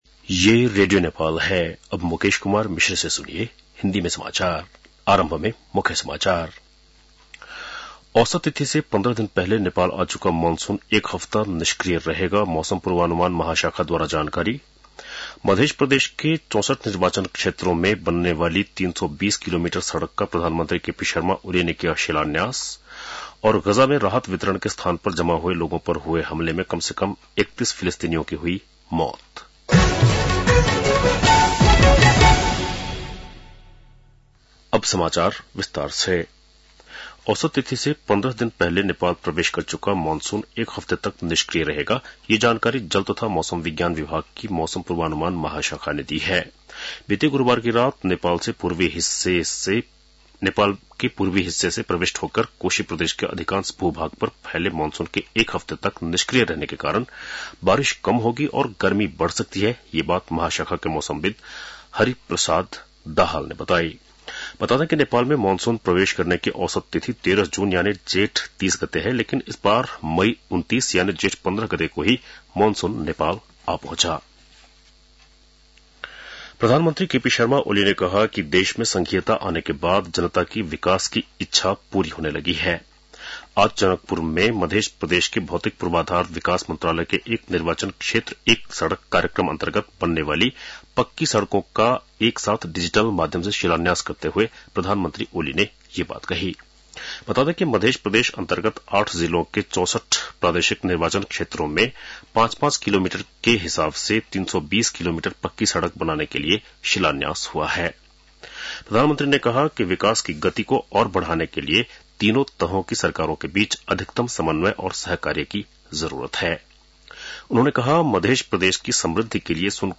बेलुकी १० बजेको हिन्दी समाचार : १८ जेठ , २०८२
10-pm-hindii-news-.mp3